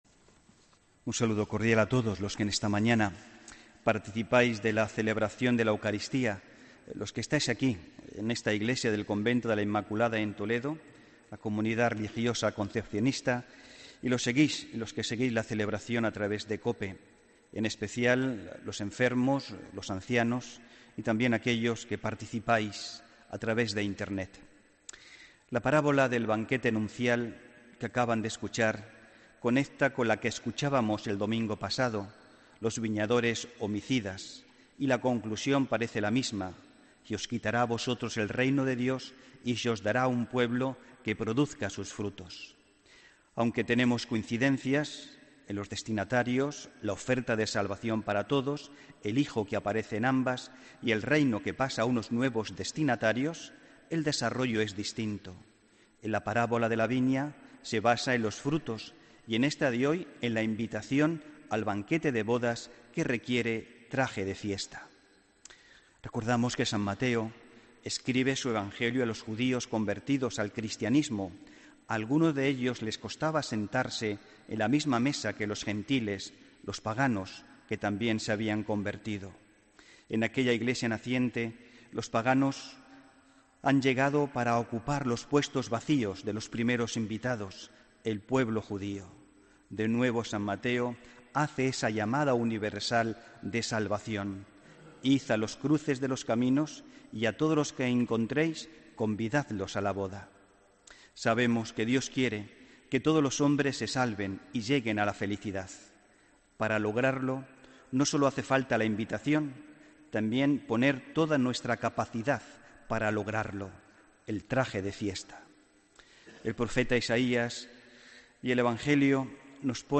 Homilía del domingo 15 de octubre de 2017